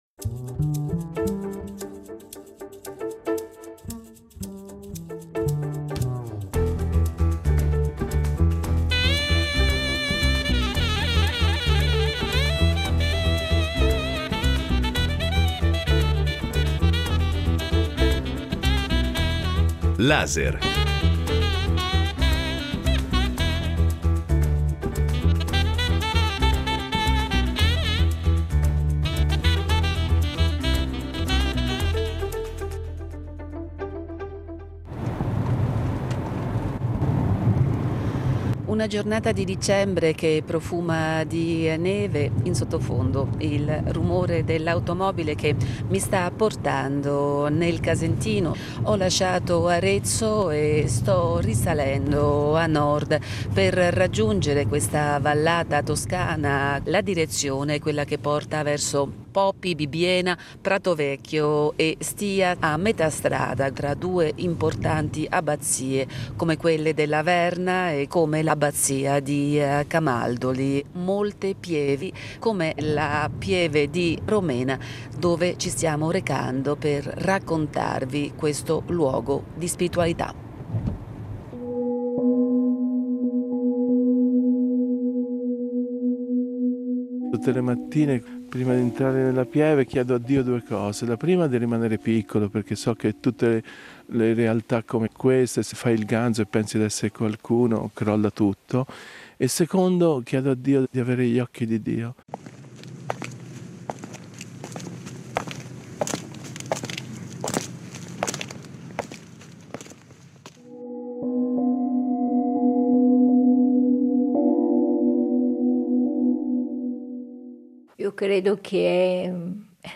Un reportage di voci, incontri